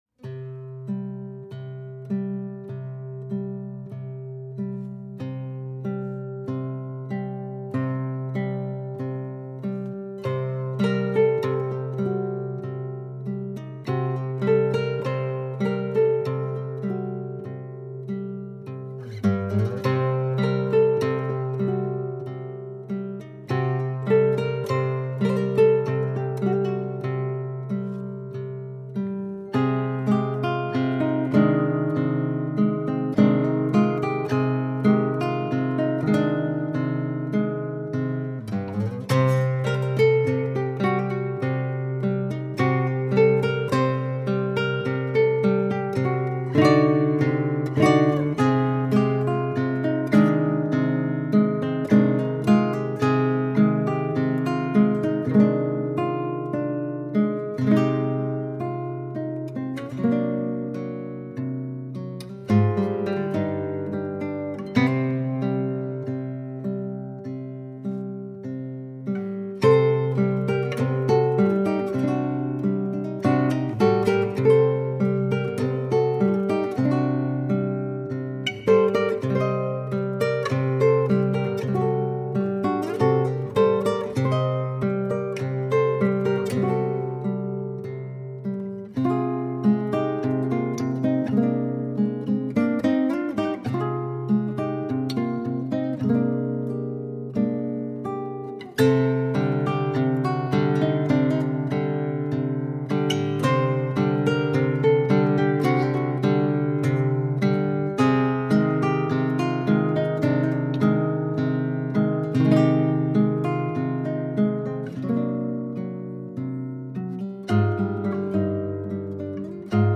Instrumentação: violão solo
Tonalidade: Cm | Gênero: instrumental brasileiro